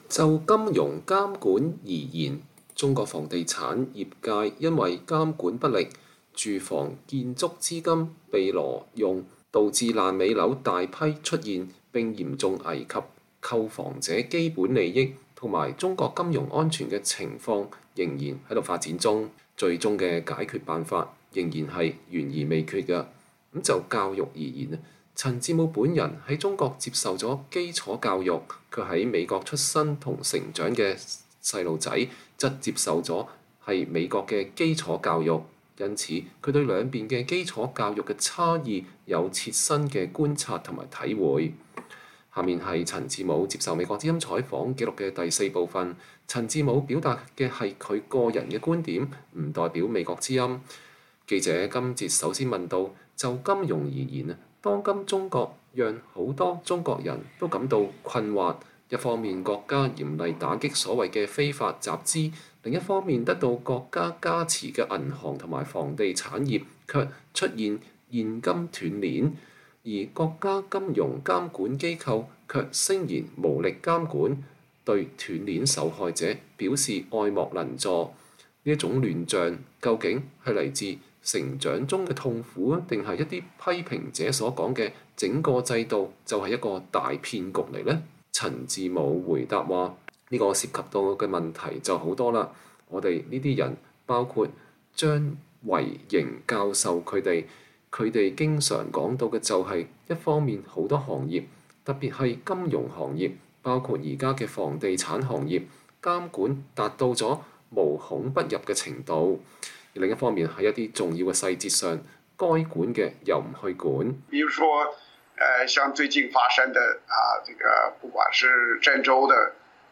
專訪陳志武（4）：談中國金融監管與教育
恰當的金融監管對中國經濟發展至關重要，開放的教育則對中國轉變為以創新立國至關重要。陳志武教授在接受美國之音採訪的時候直言不諱地表示，至少就教育而言，他的擔憂比七八年前是增加了而不是減少了。